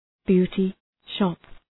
Leave a reply beauty shop Dëgjoni shqiptimin https